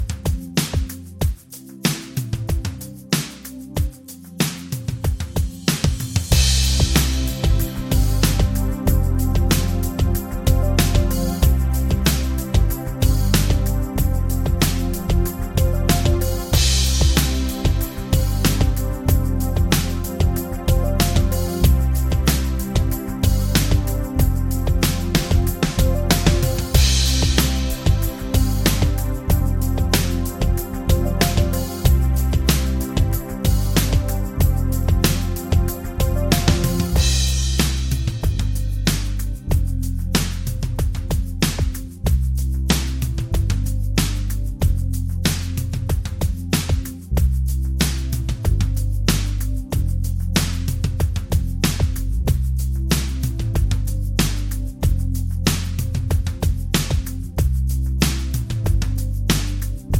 Minus Main Guitars For Guitarists 3:35 Buy £1.50